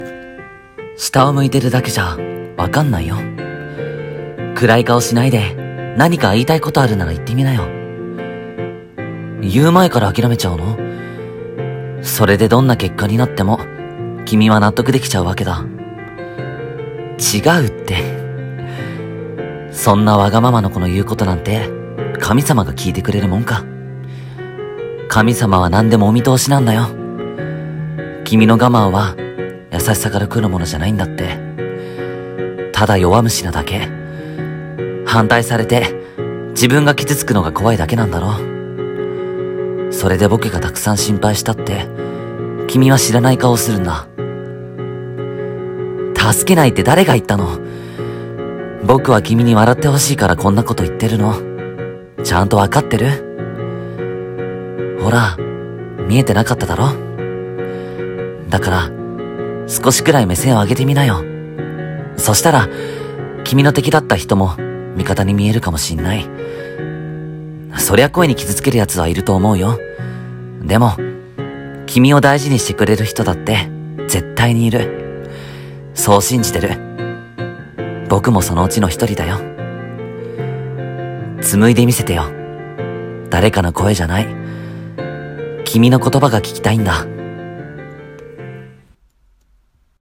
一人声劇